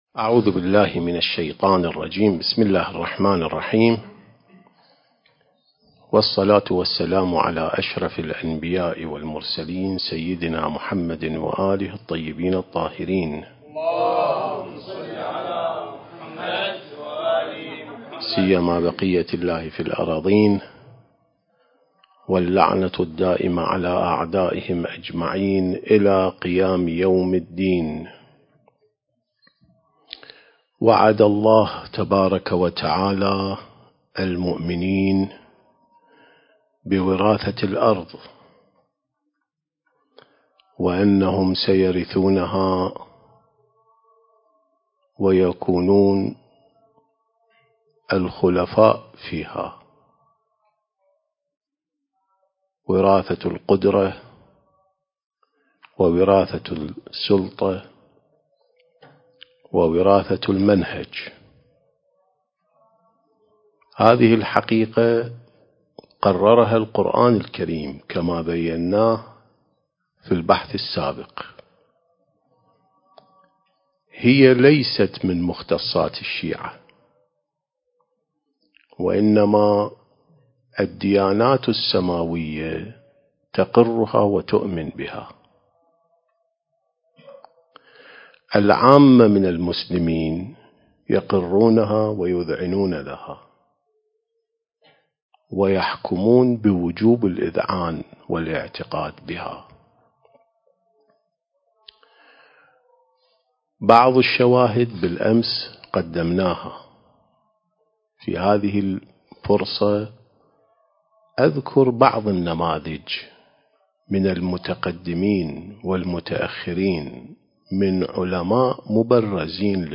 سلسة محاضرات الإعداد للمهدي (عجّل الله فرجه) (2) التاريخ: 1444 للهجرة